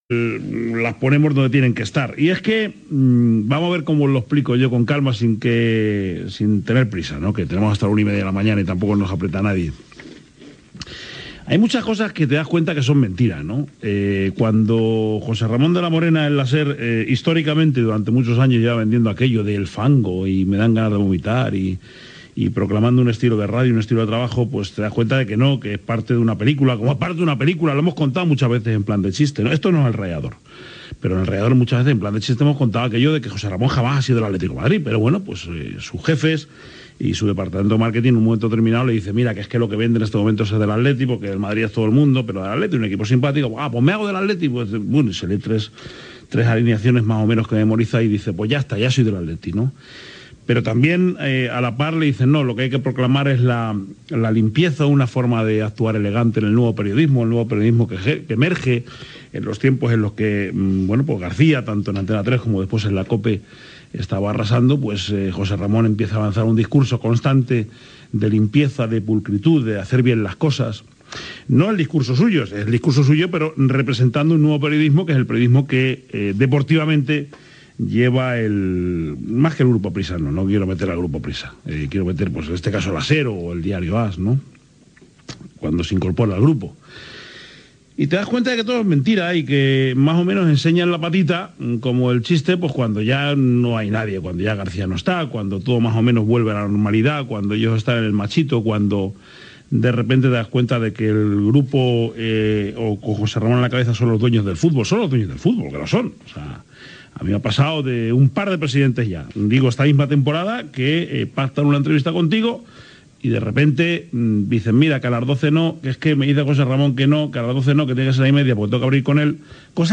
Esportiu